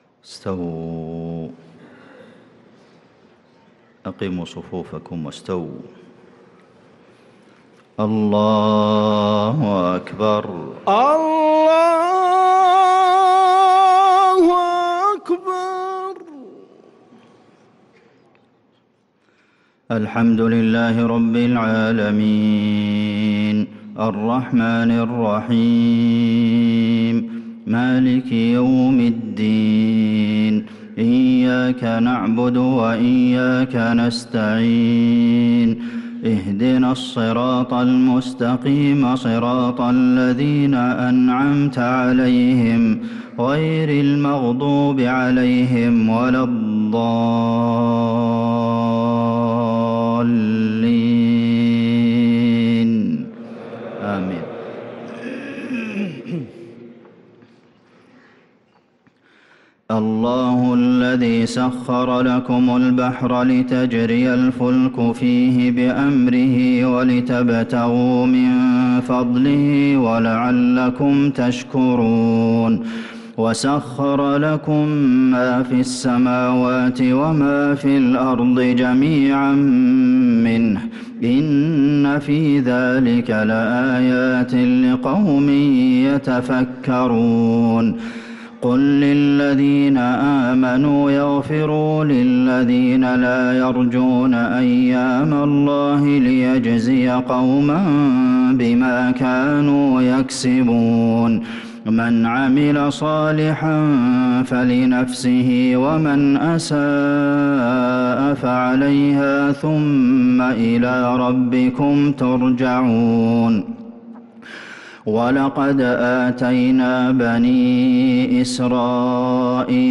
صلاة العشاء للقارئ عبدالمحسن القاسم 16 رجب 1445 هـ
تِلَاوَات الْحَرَمَيْن .